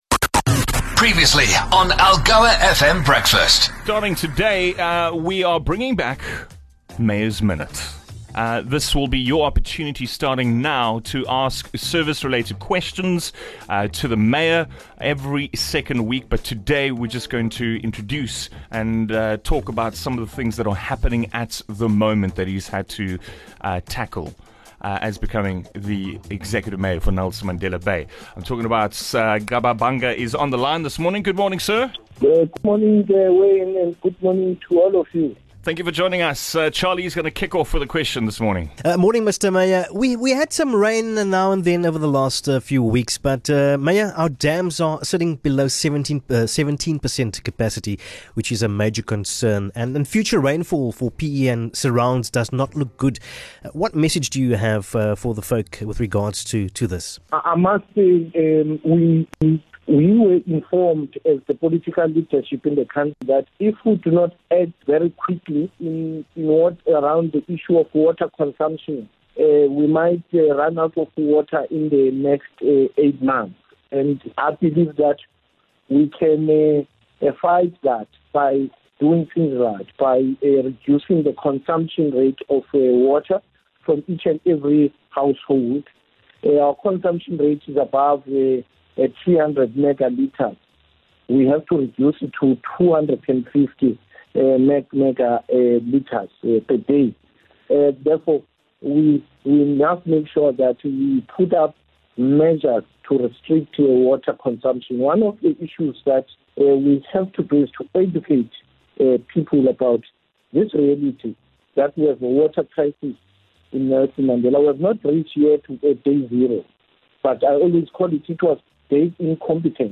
Every two weeks Mayor Nqaba Bhanga will be joining the team to answer YOUR questions. Up for discussion today were the hot topics - COVID-19, the drought crisis, and the contentious renaming of Port Elizabeth.